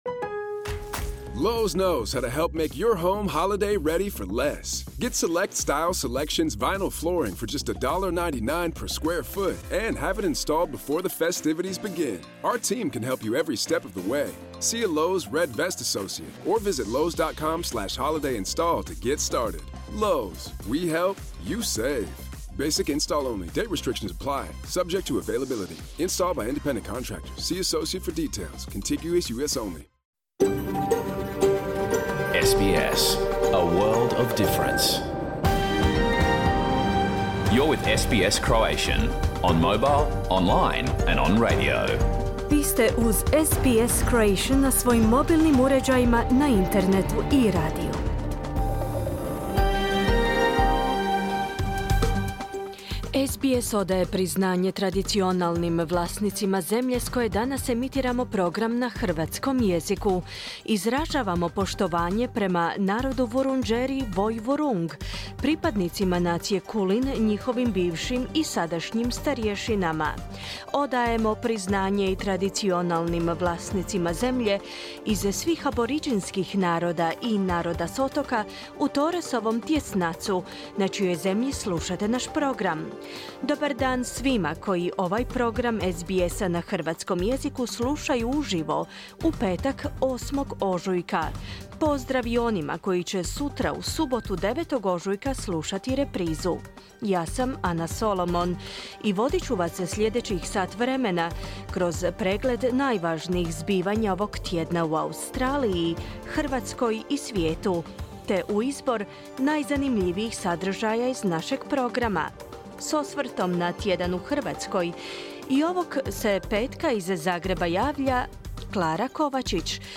Tjedni pregled zbivanja u Australiji, Hrvatskoj i ostatku svijeta. Emitirano uživo u 11 sati po istočnoaustralskom vremenu, u petak, 8. ožujka 2024. godine.